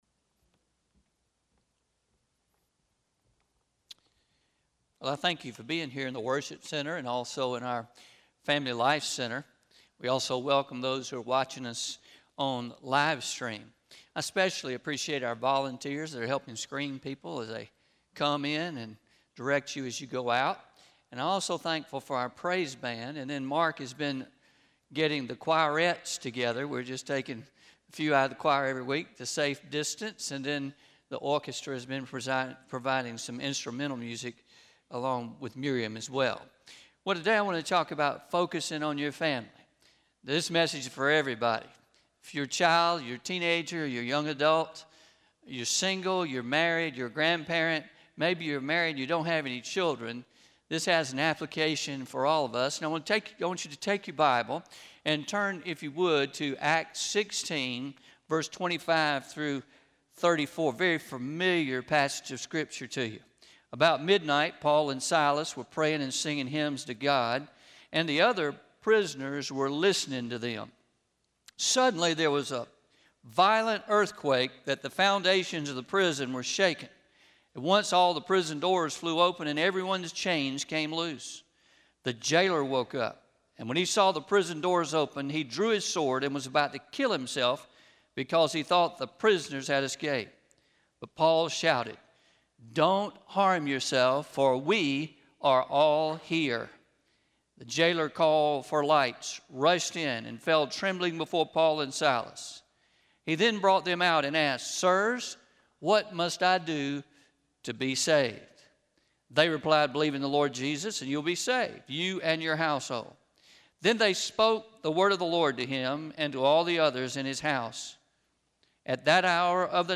08-02-20am Sermon – Focus on your Family – Traditional – Pleasant Valley South Baptist Church